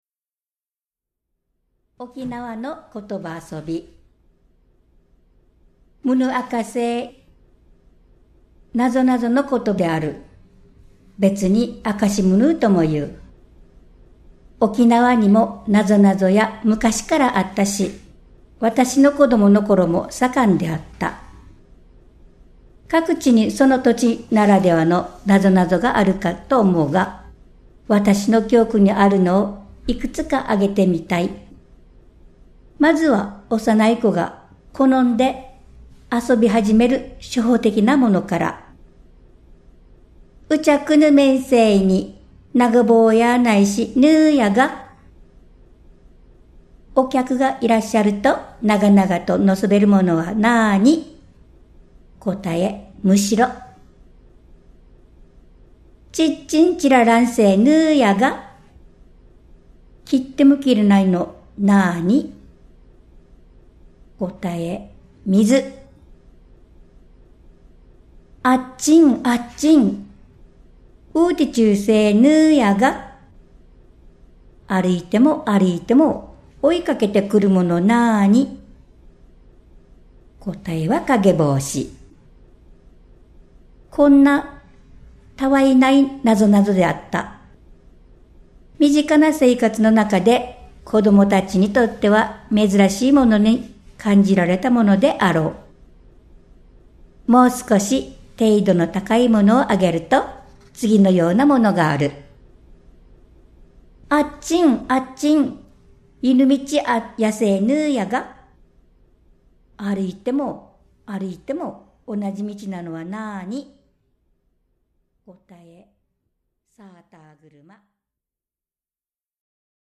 老若男女、出身も様々な9人の読み手が「うちなぁぐち」の人気コラムを朗読
ときおり、昭和時代の懐かしいひびきも聴こえてくるかもしれません。